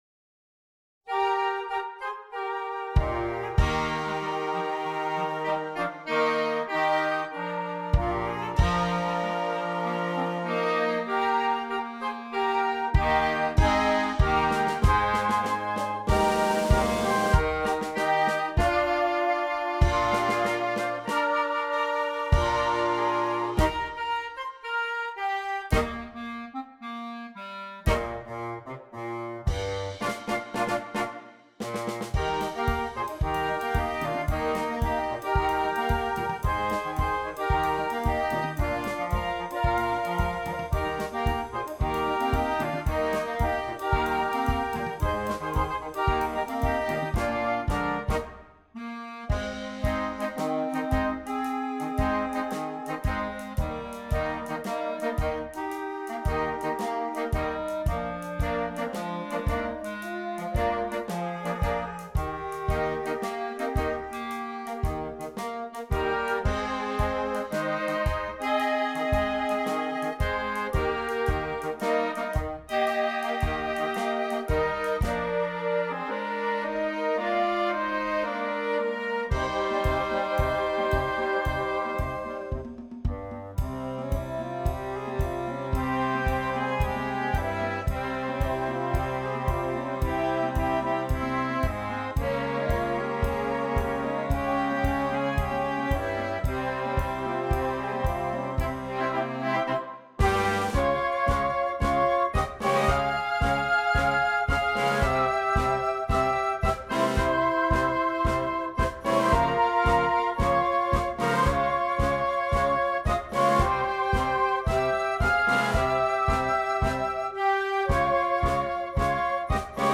Christmas
Interchangeable Woodwind Ensemble